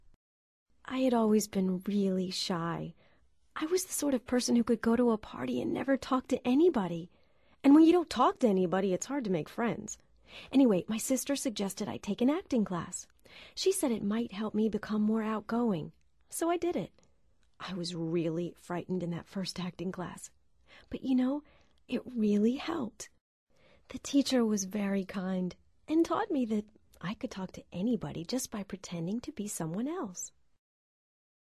First speaker
Primer orador